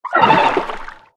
Sfx_creature_penguin_death_water_01.ogg